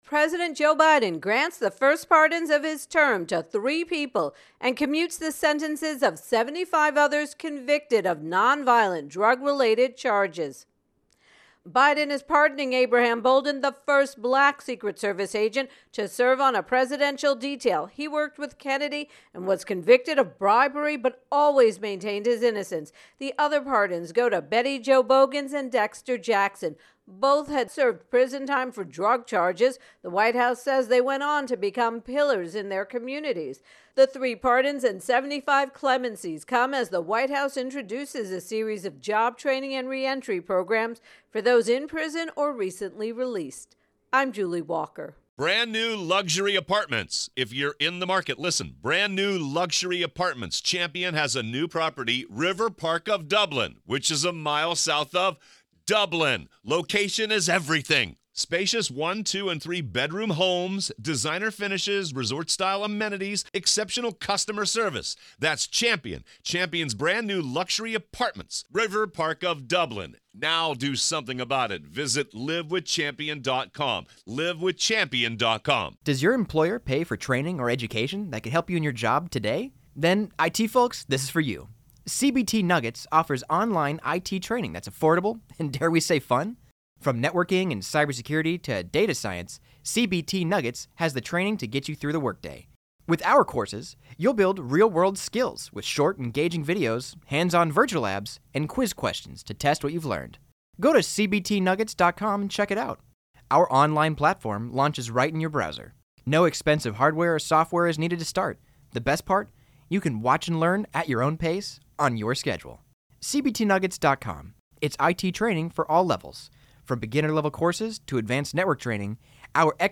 Biden Pardons intro and voicer